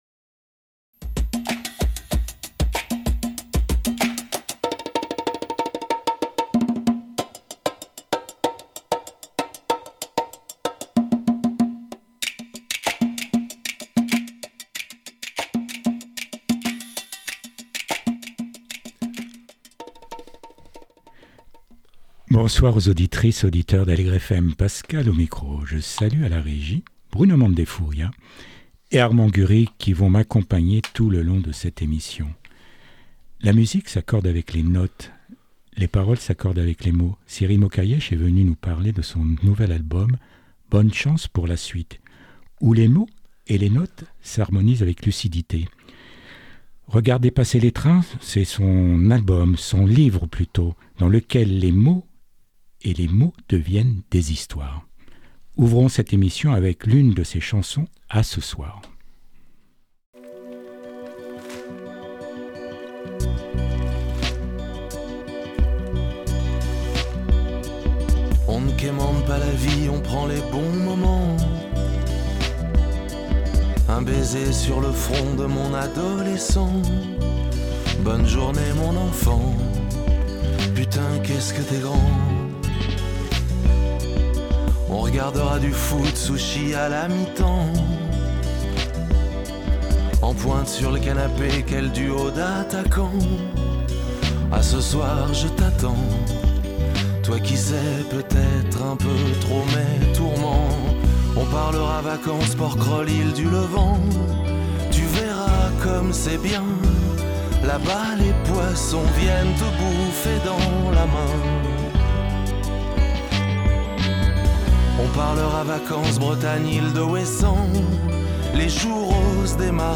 Portrait radiophonique de Cyril Mokaiesh